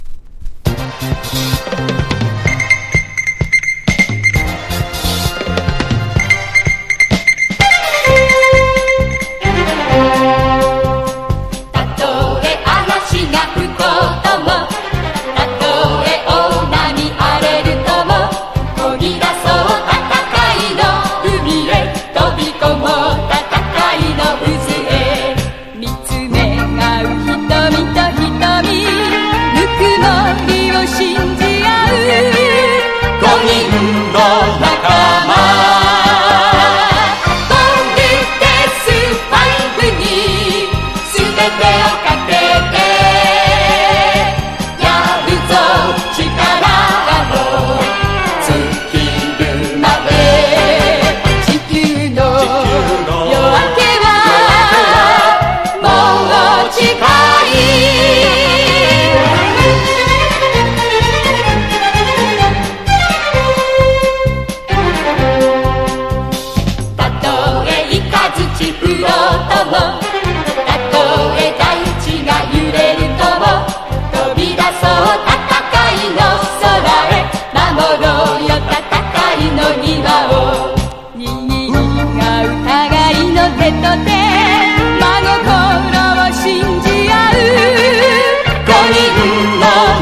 形式 : 7inch
ザ・戦隊モノな
POP